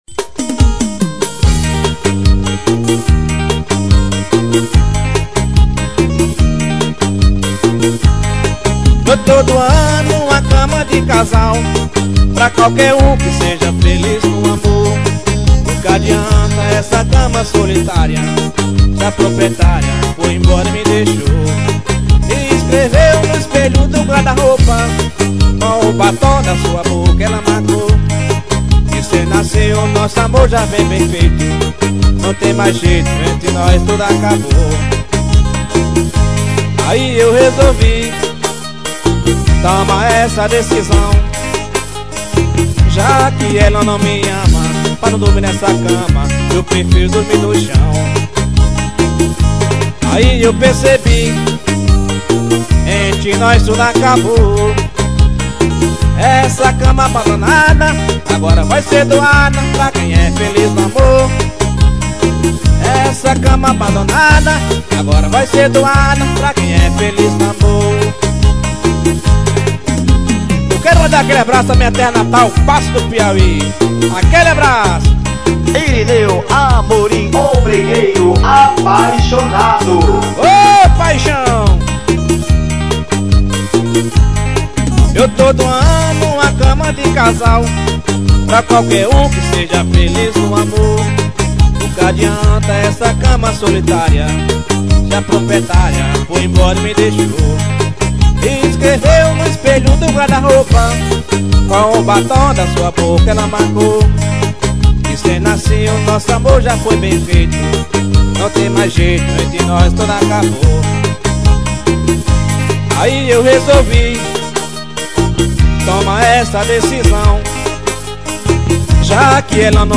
brega.